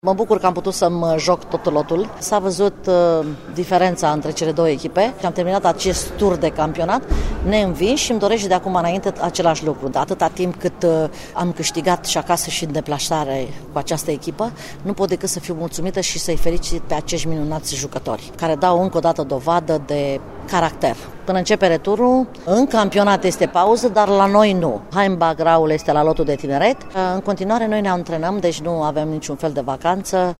Declaraţia